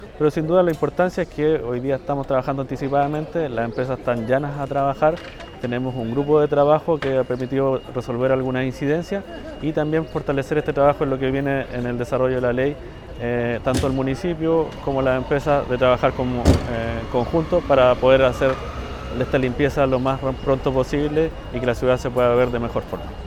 Por otro lado, el subsecretario de telecomunicaciones, Claudio Araya, agregó detalles de este retiro de cables.
cuna-claudio-araya.mp3